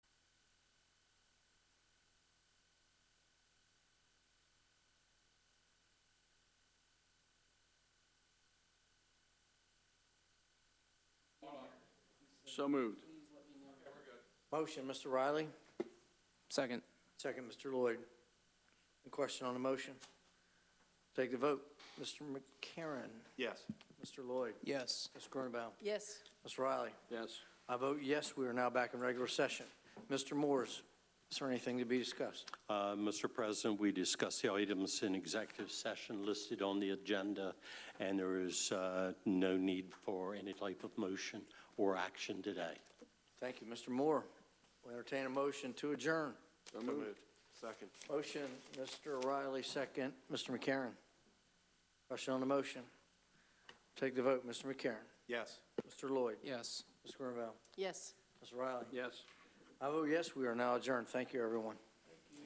Meeting location: Council Chambers, Sussex County Administrative Office Building, 2 The Circle, Georgetown
Meeting type: County Council